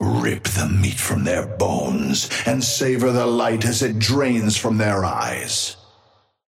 Amber Hand voice line - Rip the meat from their bones, and savor the light as it drains from their eyes!
Patron_male_ally_wrecker_start_04.mp3